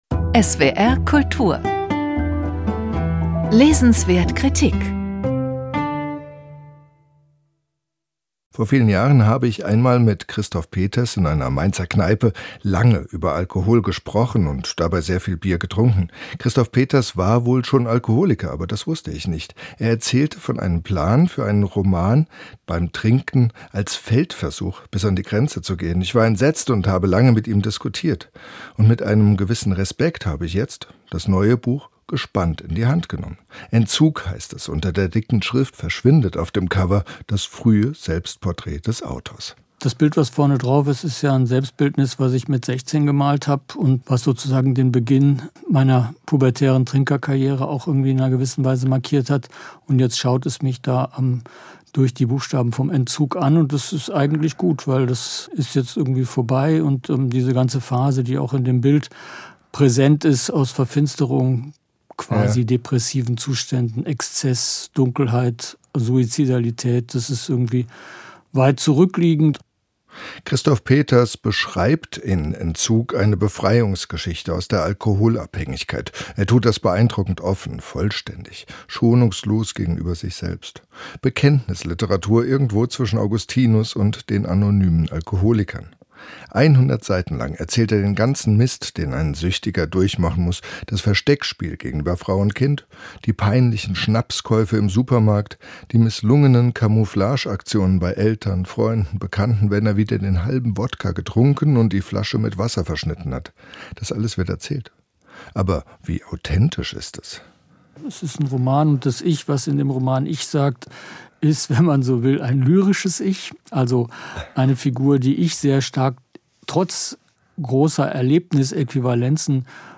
Buchkritik